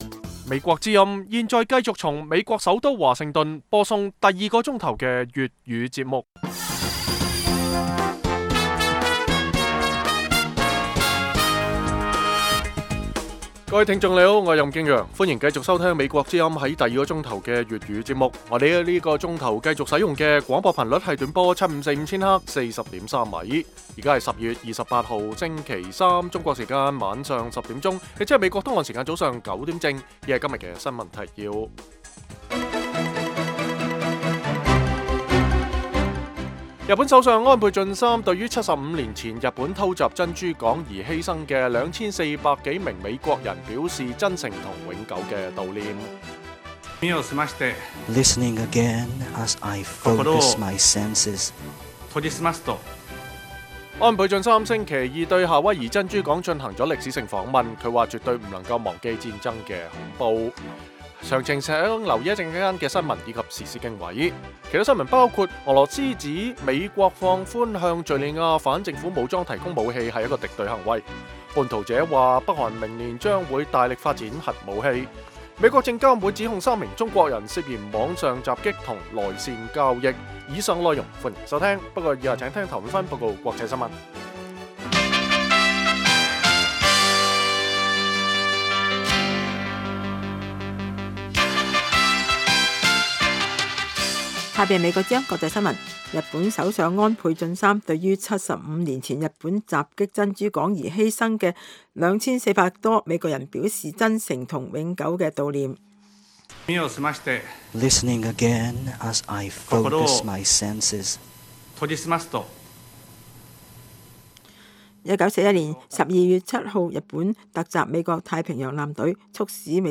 粵語新聞 晚上10-11點
北京時間每晚10－11點 (1400-1500 UTC)粵語廣播節目。內容包括國際新聞、時事經緯和社論。